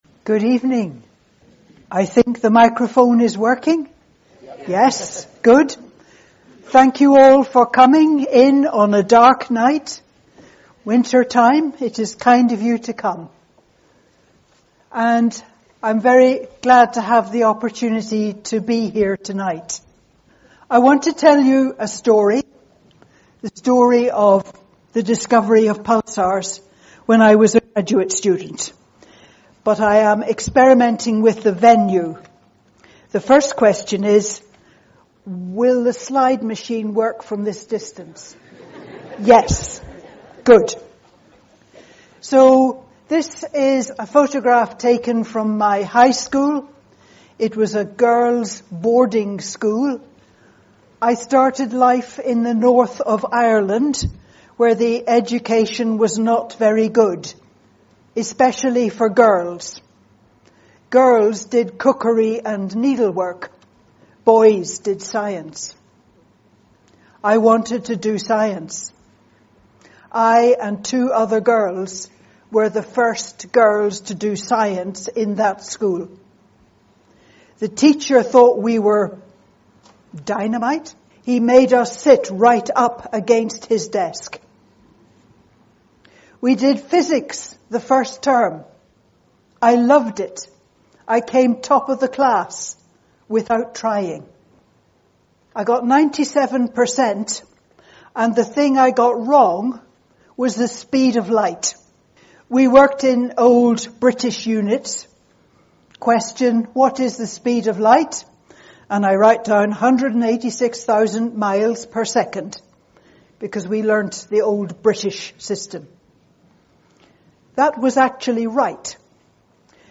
In this talk Jocelyn Bell Burnell will describe the discovery of pulsars and reflect on the circumstances that led to the discovery in that way at that time.